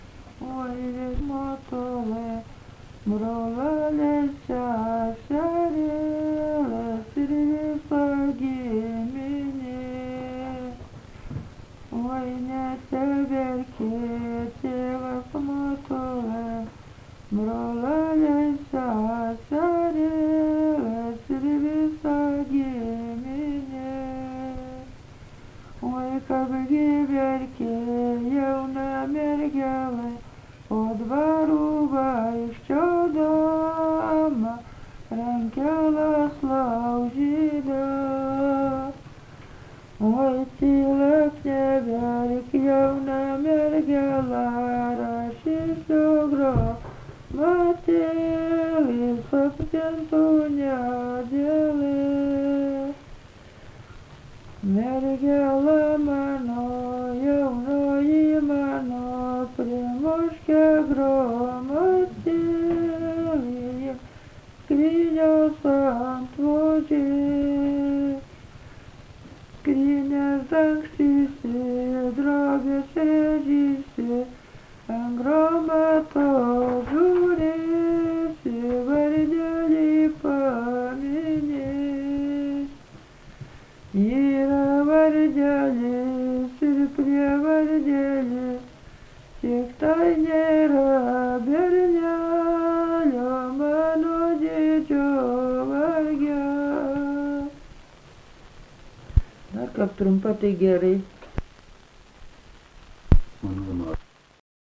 Dalykas, tema daina
Erdvinė aprėptis Gudakiemio k.
Atlikimo pubūdis vokalinis